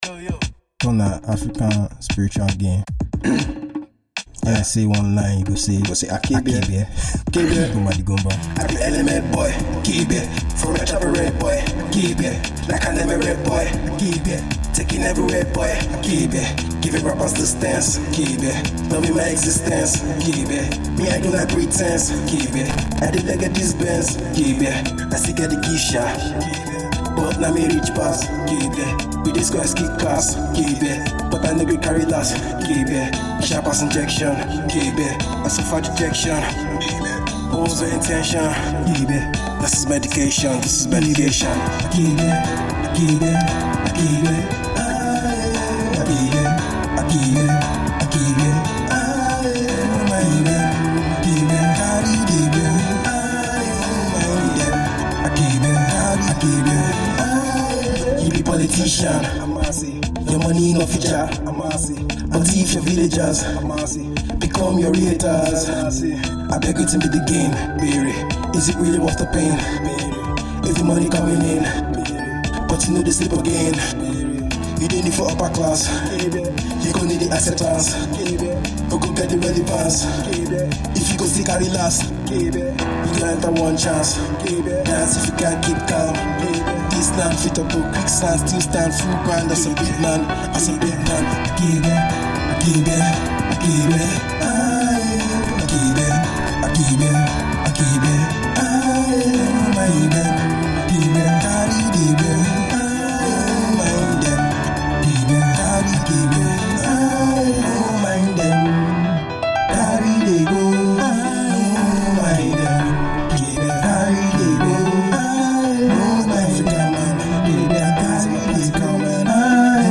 another deep and lyrical piece in this rap song